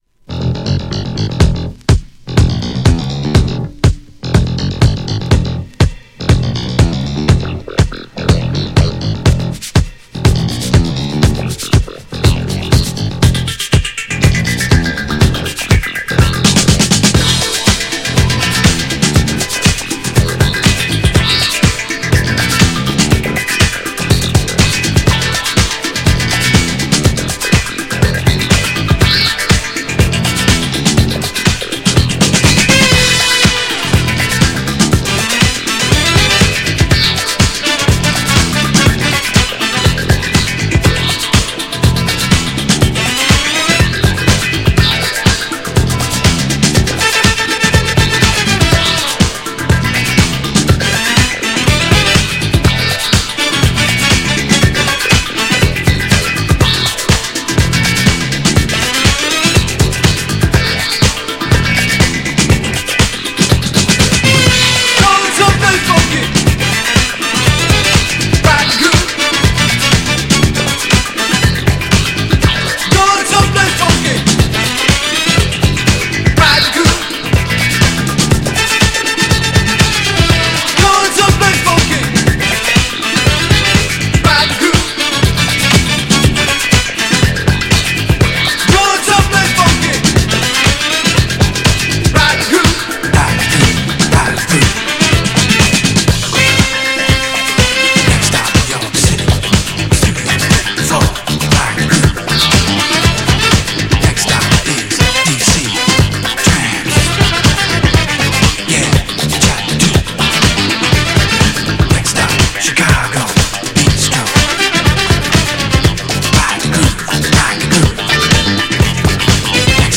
GENRE Dance Classic
BPM 116〜120BPM